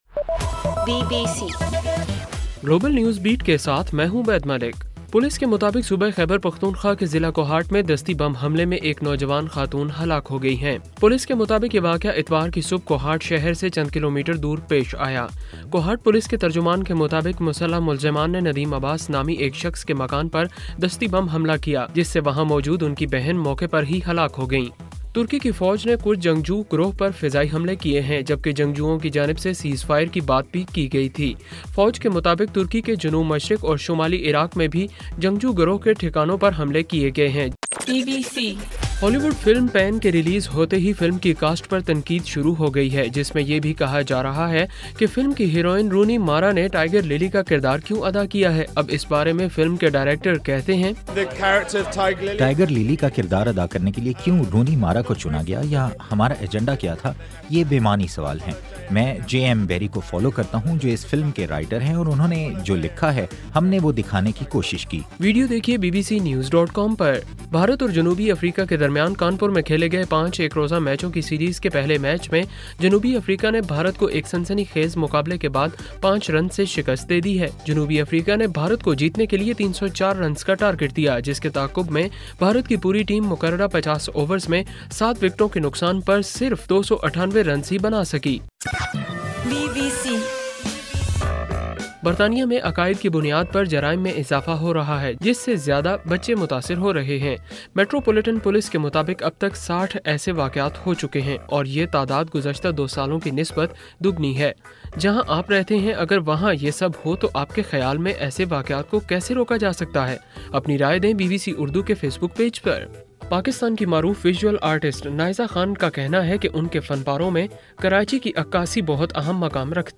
اکتوبر 12:صبح 1 بجے کا گلوبل نیوز بیٹ بُلیٹن